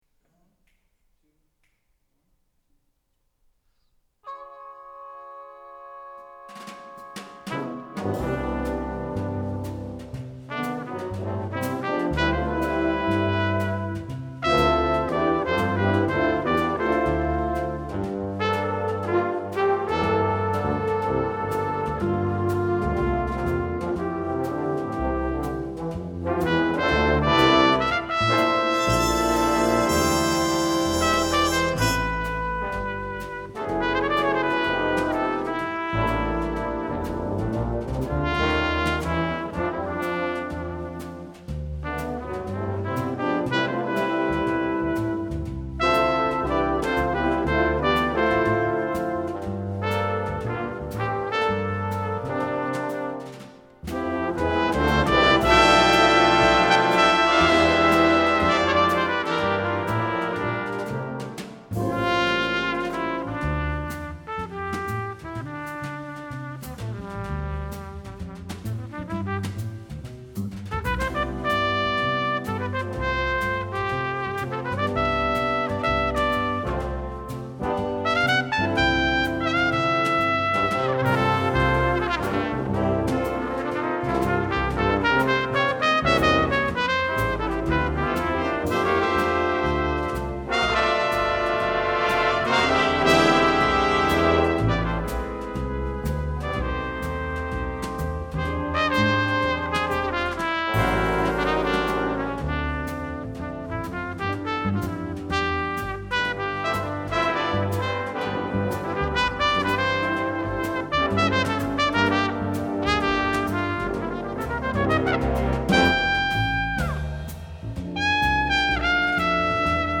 Brass Ensemble